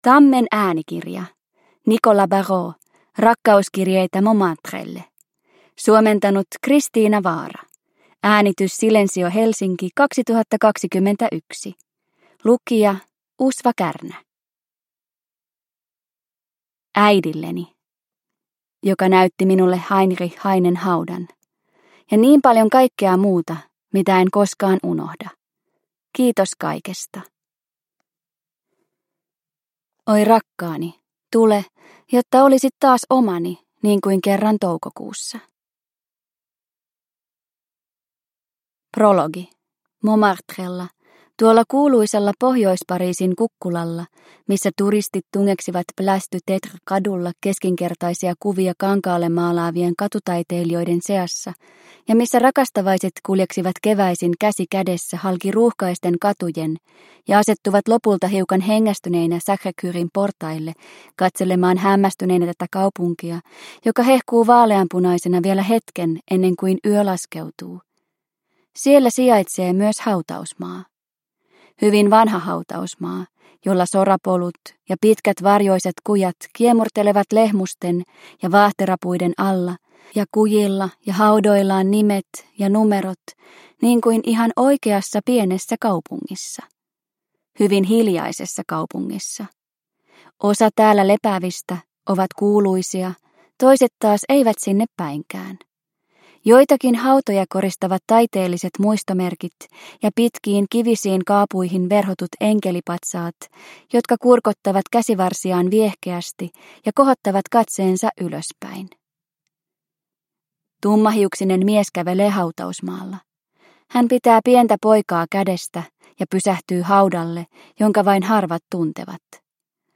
Rakkauskirjeitä Montmartrelle (ljudbok) av Nicolas Barreau